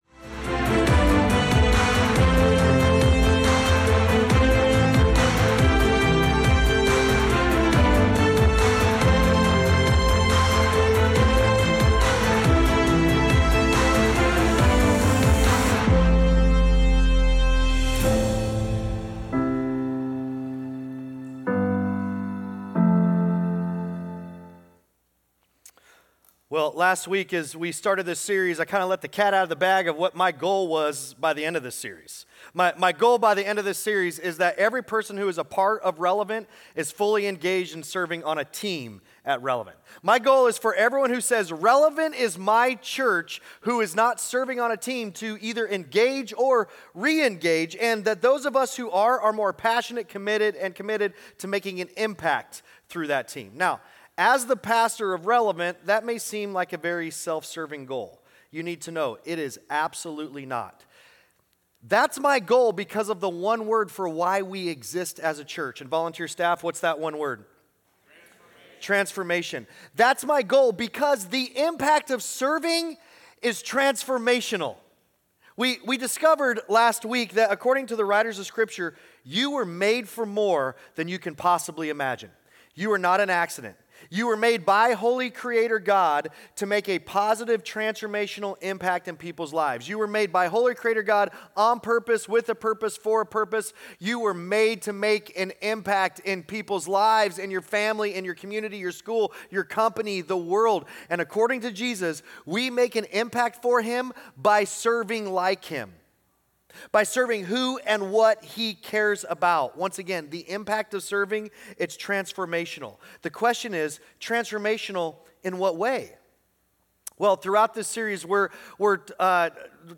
Sunday Sermons Made for More, Part 2: "More For Us" Aug 10 2025 | 00:38:43 1x 00:00 / 00:38:43 Subscribe Share Apple Podcasts Spotify Overcast RSS Feed Share Link Embed